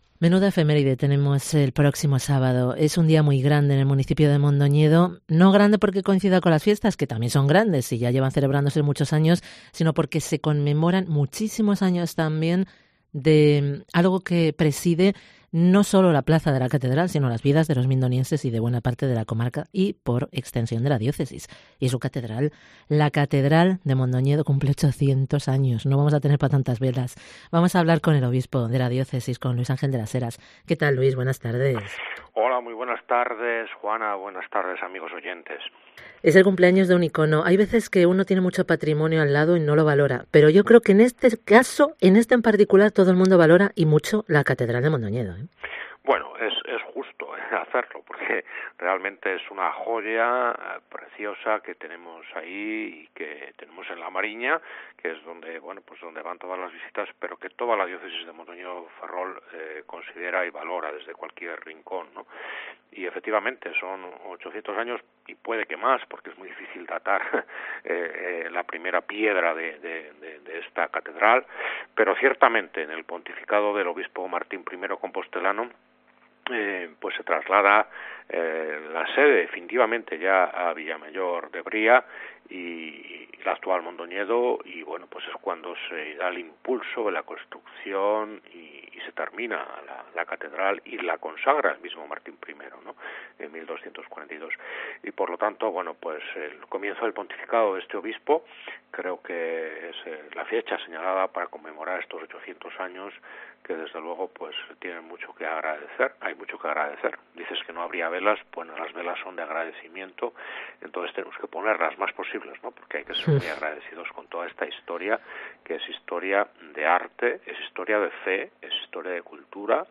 ENTREVISTA CON EL OBISPO DE MONDOÑEDO